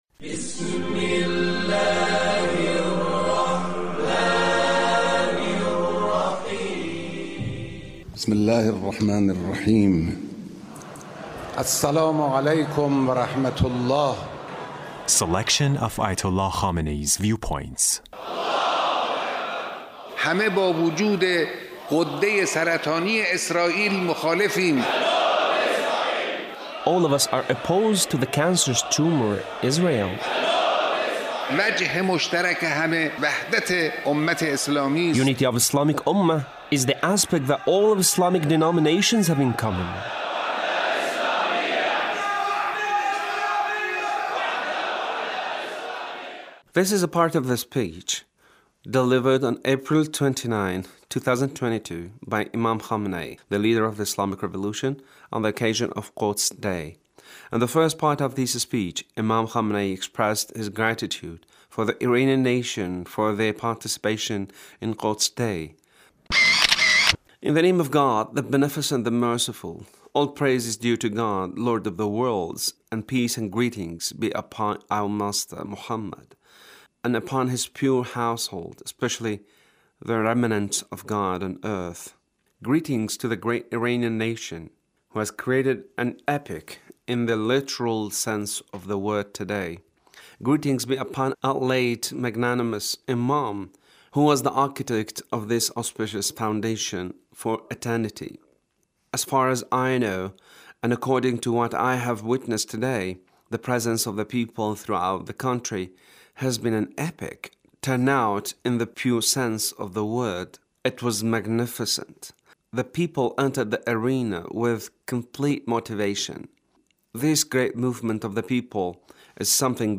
The Leader's speech on Quds Day